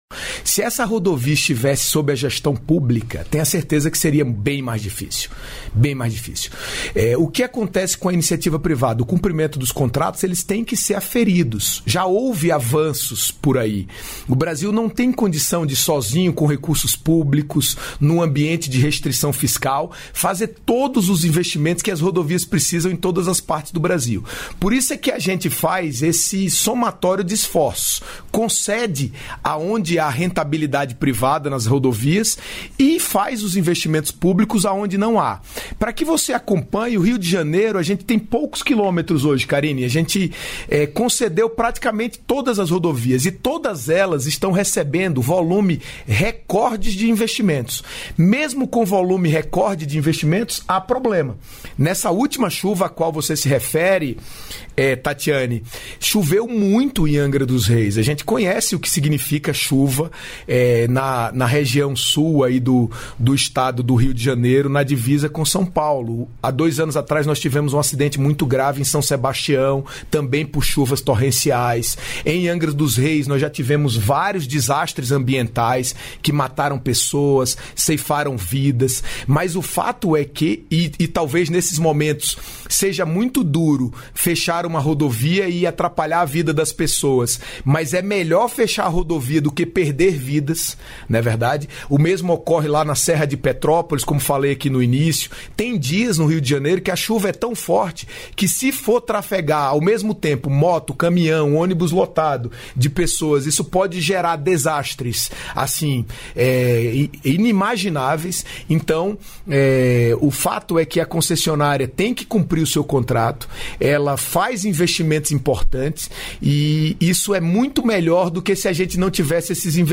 Trecho da participação do ministro dos Transportes, Renan Filho, no programa "Bom Dia, Ministro" desta quinta-feira (10), nos estúdios da EBC em Brasília (DF).